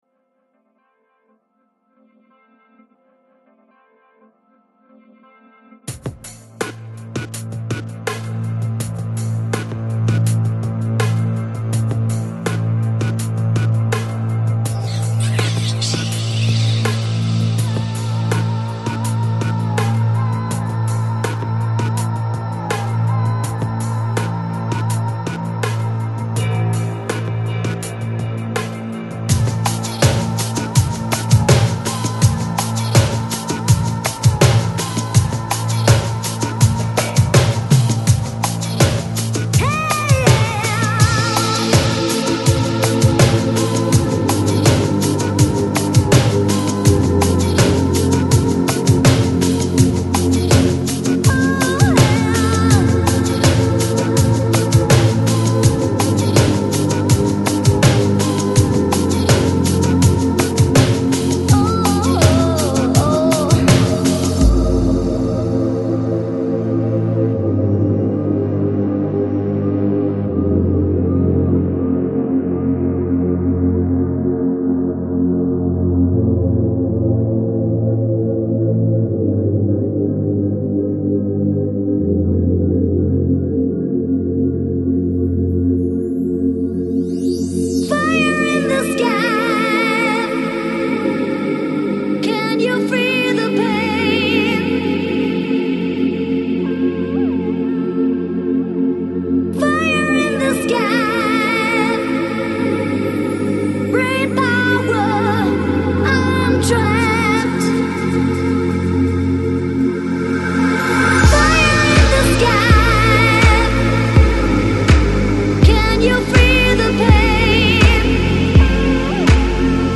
Жанр: Electronic, Chill Out, Lounge